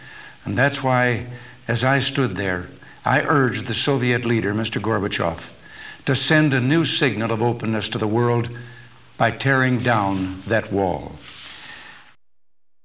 Ecouter son bla, bla, bla ?1987Berlin Ouest. Discours de Ronald REAGAN qui met Mikhaïl GORBATCHEV au défi d'abattre le mur qui sépare la ville.